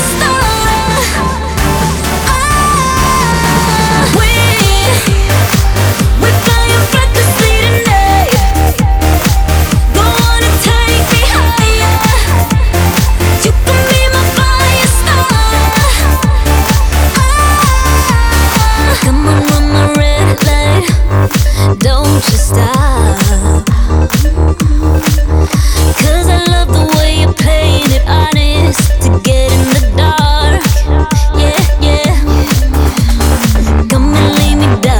Pop Rock
Жанр: Поп музыка / Рок